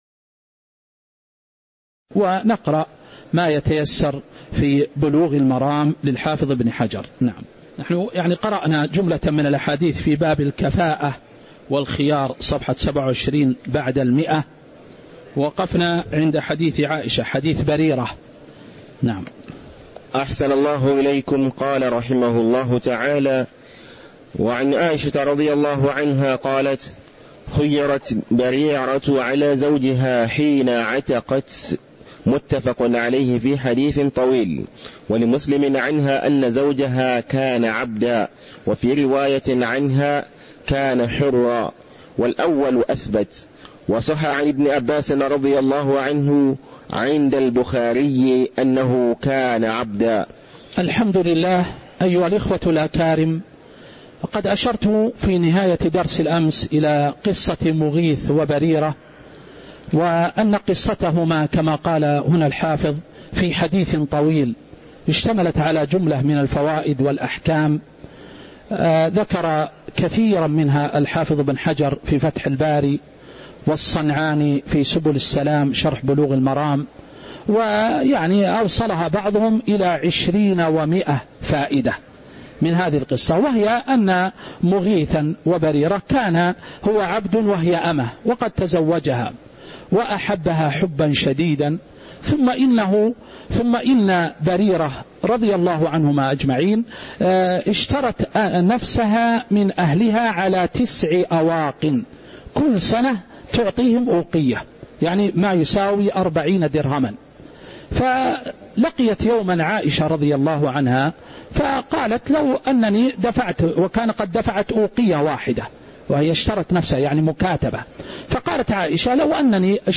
تاريخ النشر ٢٤ شعبان ١٤٤٦ هـ المكان: المسجد النبوي الشيخ